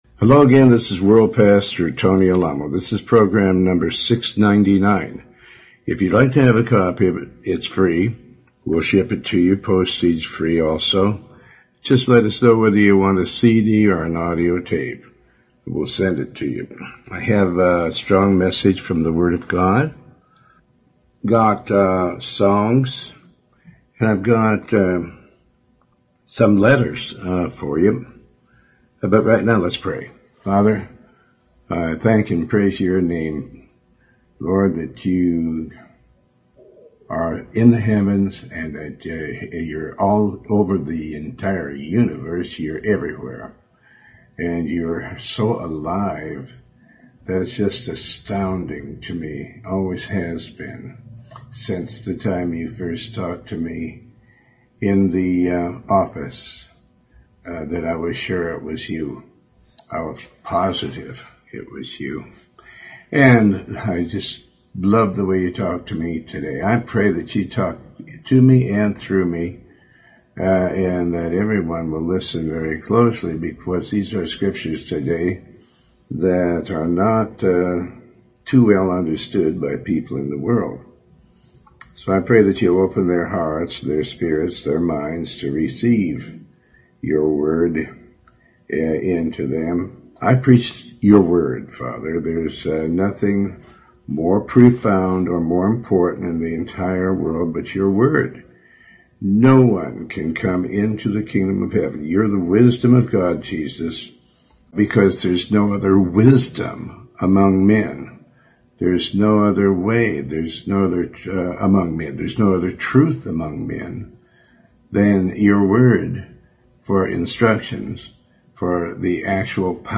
Talk Show Episode
Show Host Pastor Tony Alamo